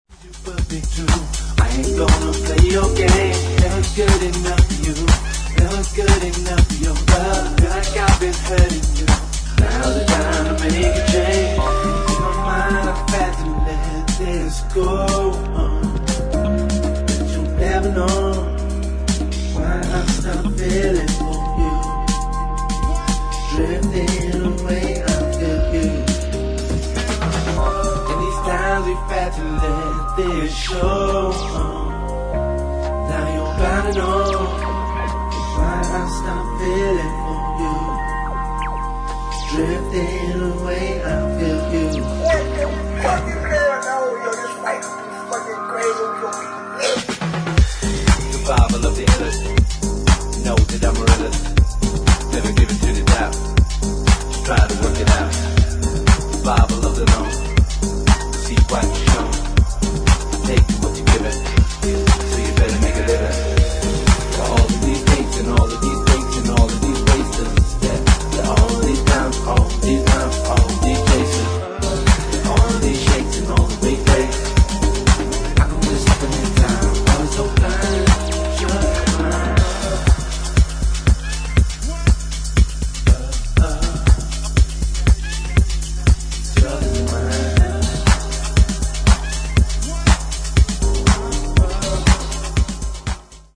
[ HOUSE / BROKEN BEAT ]
(Remix)